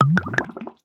Minecraft Version Minecraft Version latest Latest Release | Latest Snapshot latest / assets / minecraft / sounds / block / brewing_stand / brew1.ogg Compare With Compare With Latest Release | Latest Snapshot
brew1.ogg